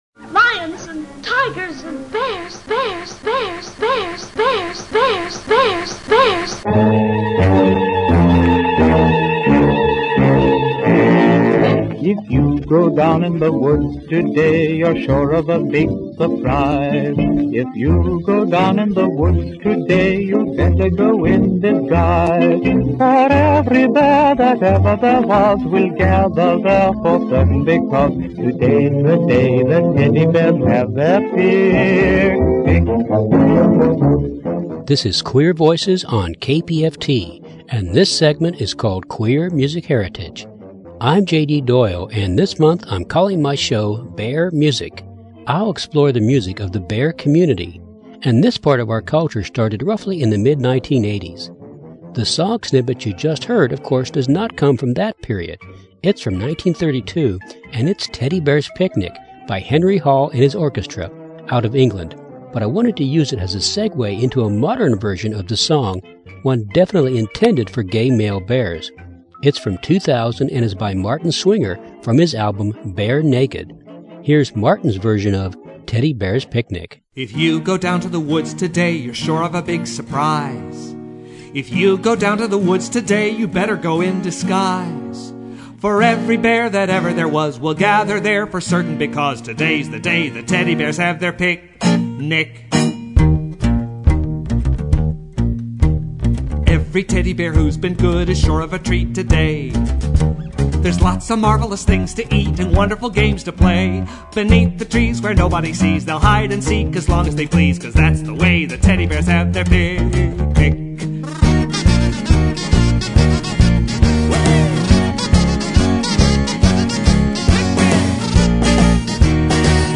This show is my tribute to the Bear Community, and the music of its artists. I'll explore some of its musical history, which will be enhanced by interviews with some of its main contributors.
And the shows also include selected quotes by several other artists, humorous interludes, and I hope, an entertaining experience.